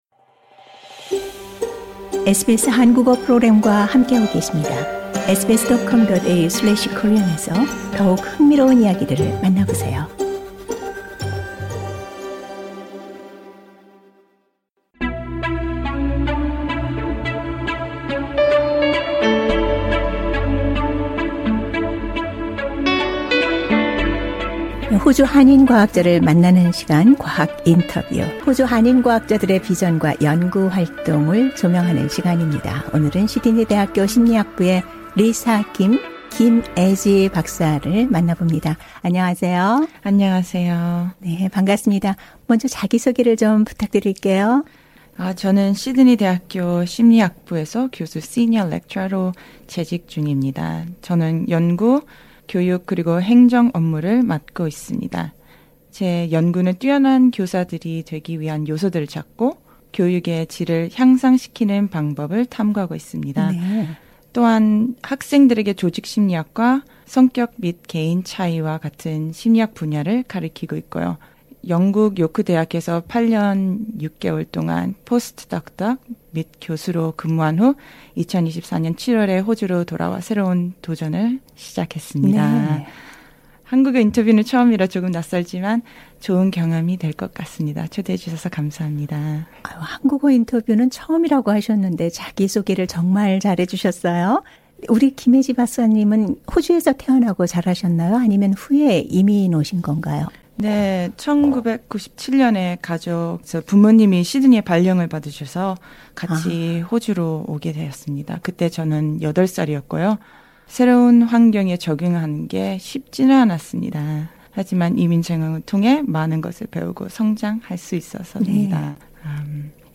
과학인터뷰: 교사의 웰빙 증진 연구